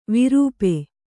♪ virūpe